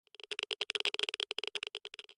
Minecraft Version Minecraft Version latest Latest Release | Latest Snapshot latest / assets / minecraft / sounds / ambient / nether / basalt_deltas / click5.ogg Compare With Compare With Latest Release | Latest Snapshot
click5.ogg